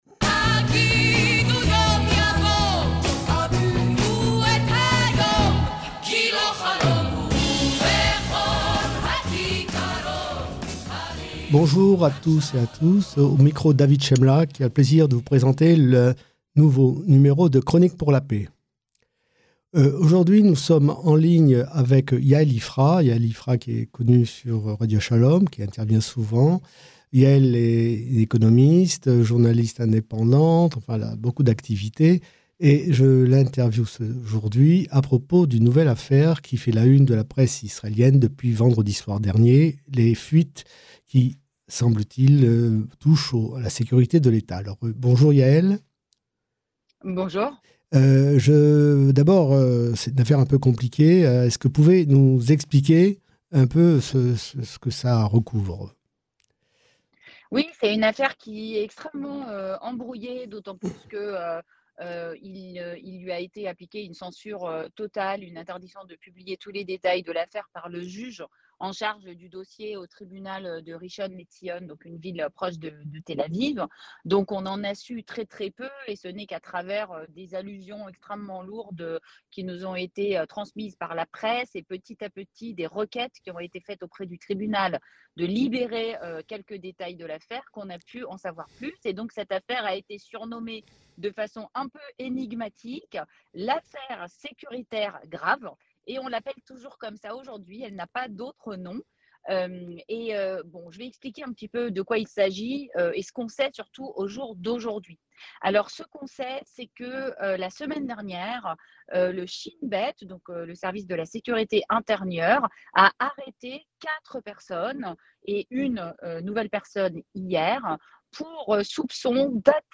émission bimensuelle parrainée par La Paix Maintenant et JCall sur Radio Shalom